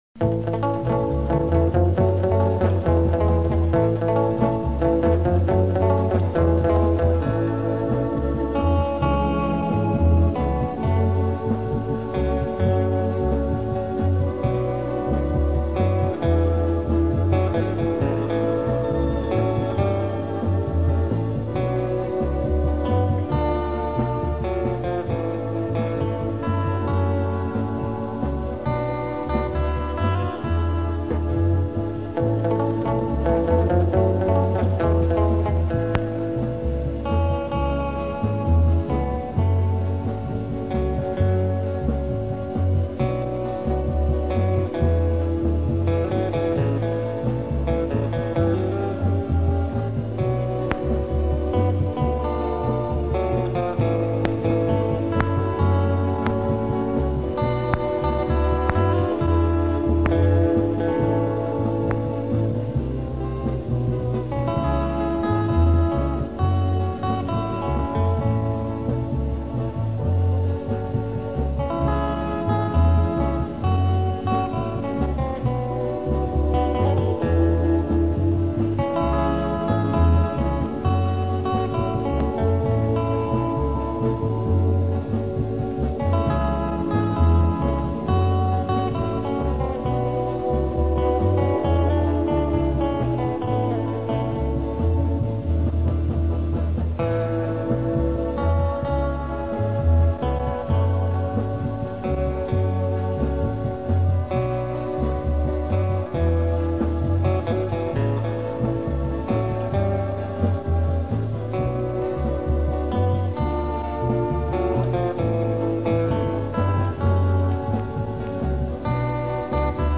instrumentale låter
I mars 1965 spilte vi inn ni melodier på bånd